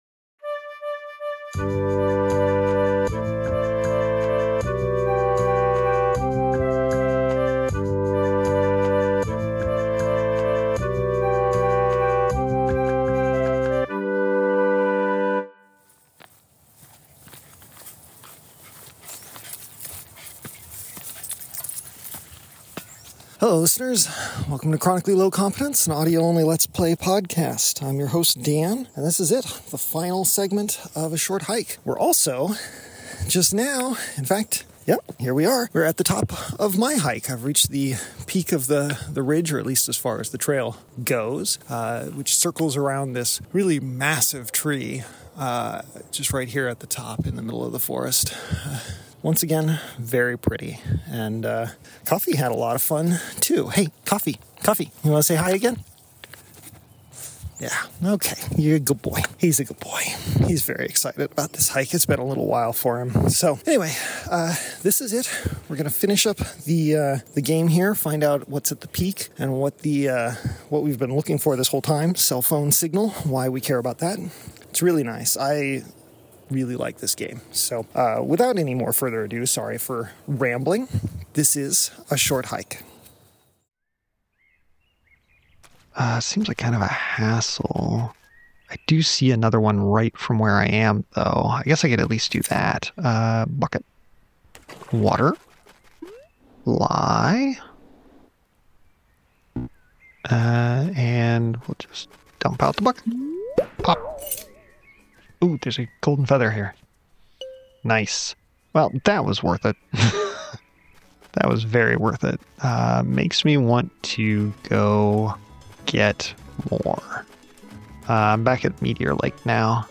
Chronically Low Competence: An Audio Only Let's Play Podcast - S2E4 - A Short Hike - What's Up Here?